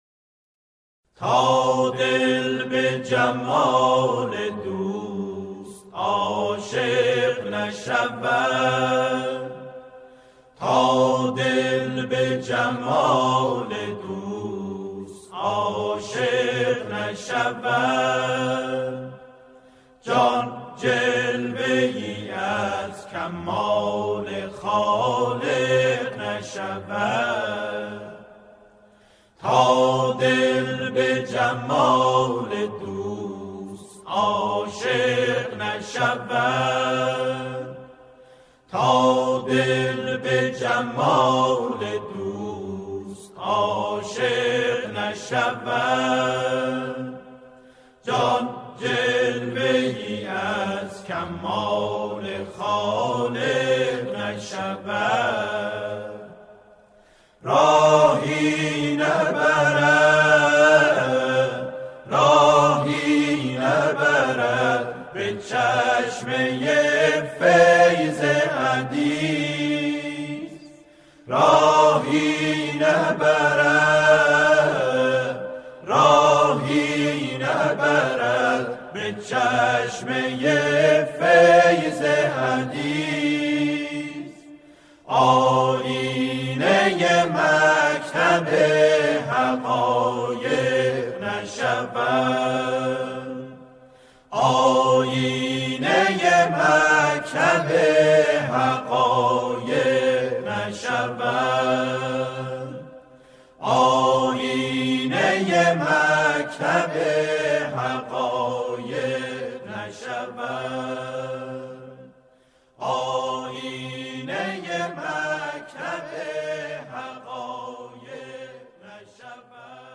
آکاپلا
به صورت آکاپلا اجرا می‌شود
تیتراژ برنامه رادیویی